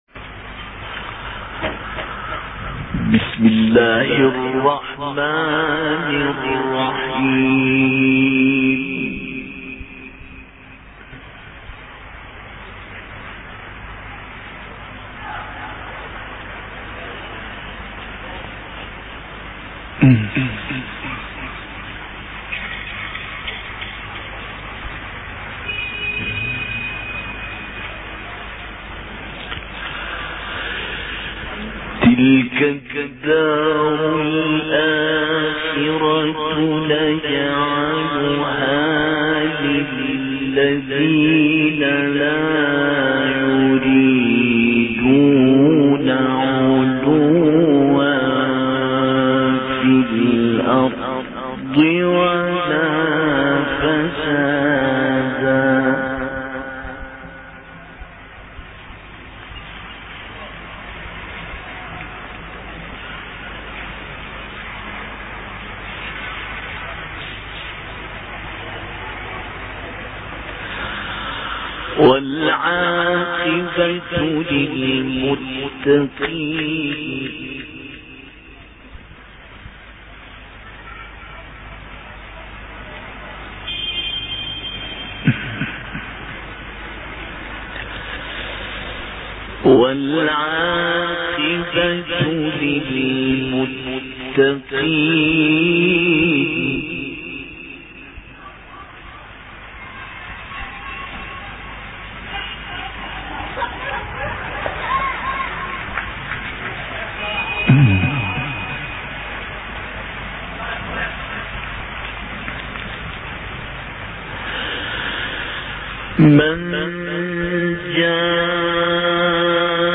Quran recitations